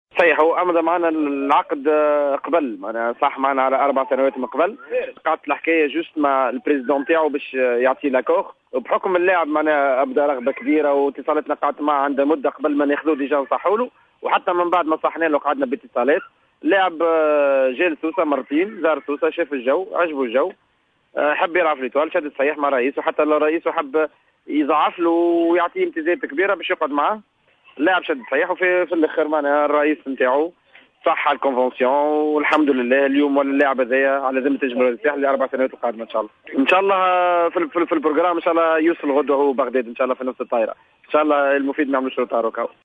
أكثر تفاصيل مع المدير الرياضي للنجم الساحلي السيد زياد الجزيري :